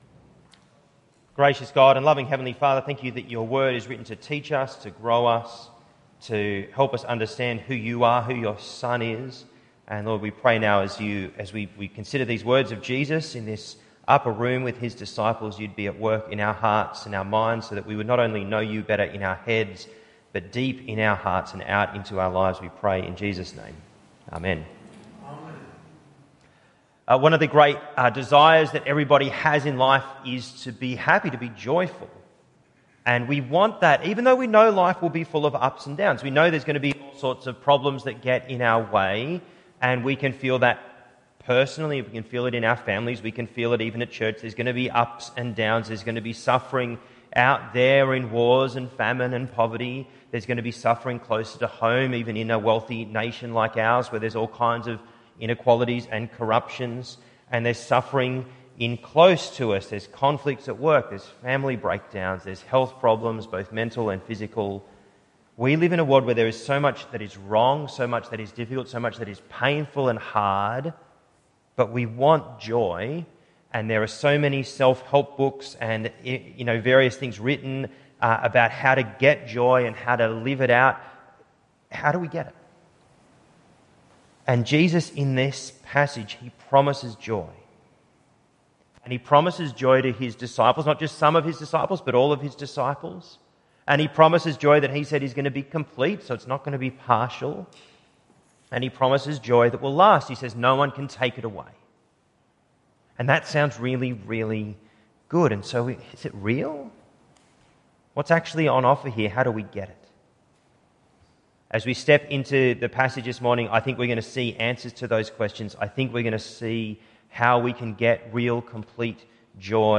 St Barnabas Sermons Podcast - Glorified: The Farewell Discourse | Free Listening on Podbean App